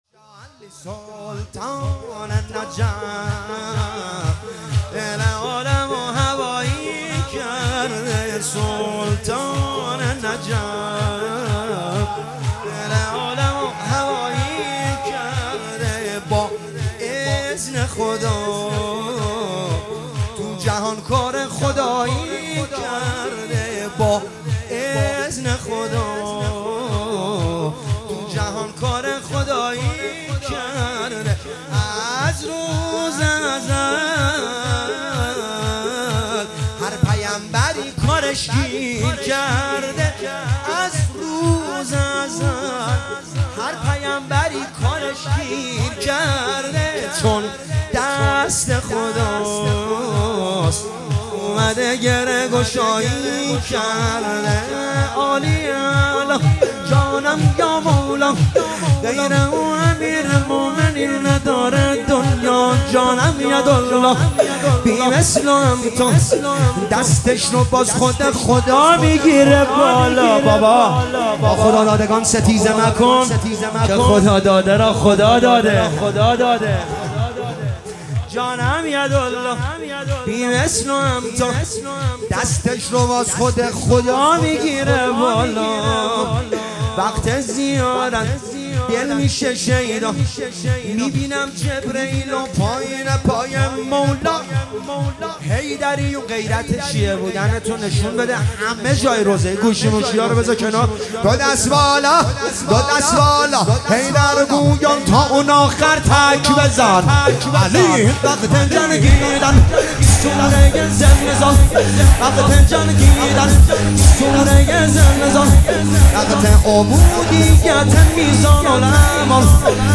شور
هیئت حسن بن علی اصفهان